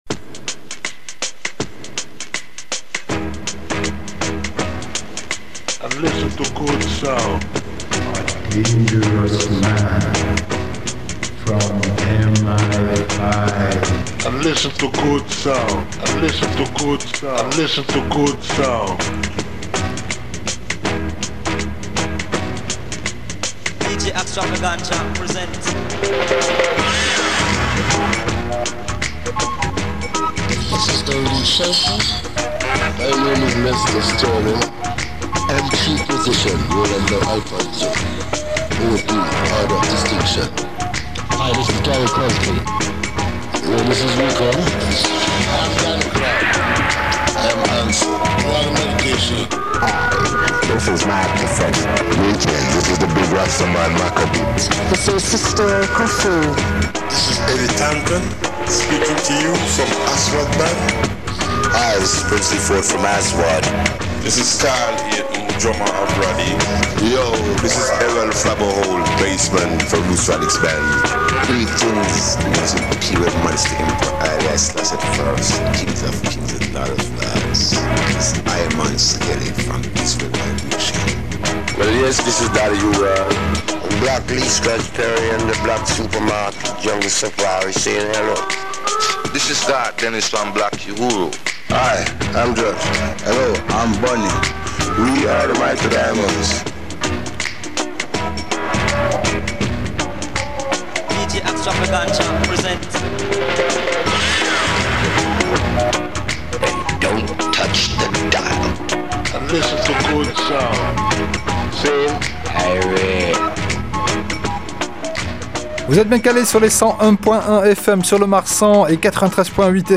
Black Super Market – radio show !
dub, salsa, funk, mestizo, ska, afrobeat, reggaeton, kompa, rumba, reggae, soul, cumbia, ragga, soca, merengue, Brésil, champeta, Balkans, latino rock…